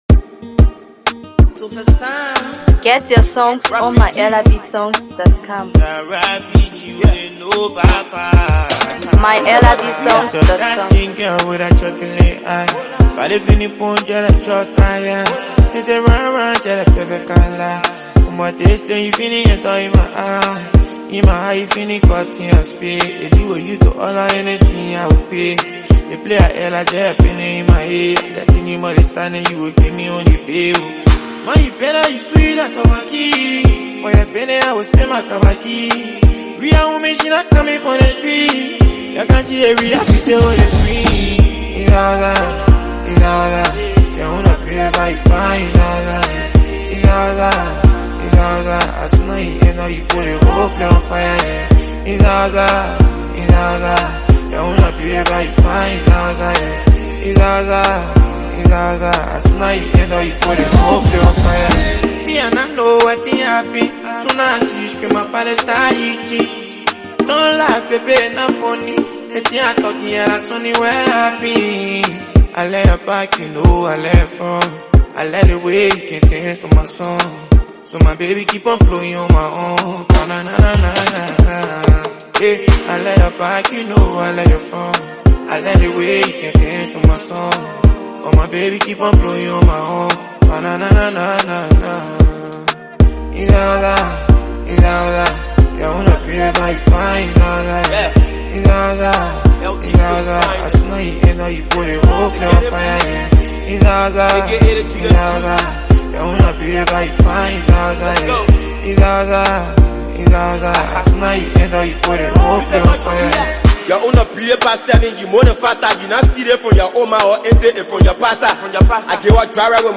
Afro PopMusic
smooth production and catchy rhythm